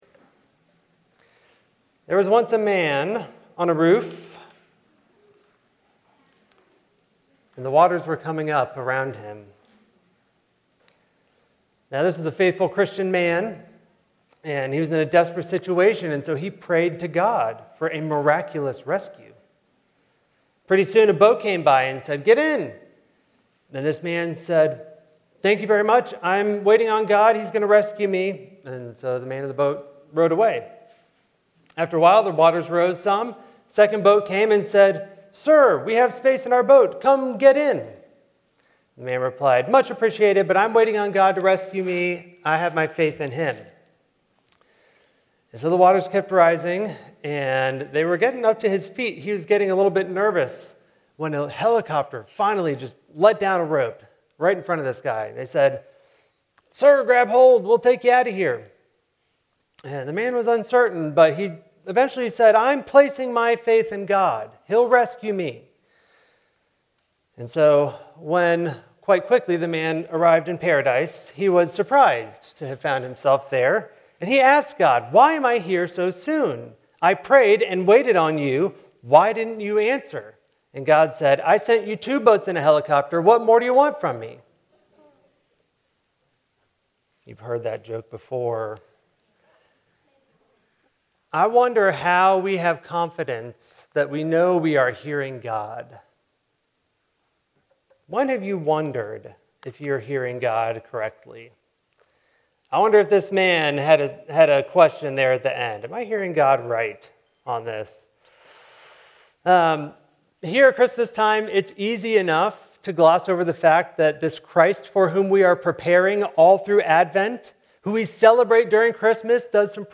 message from the Fourth Sunday of Advent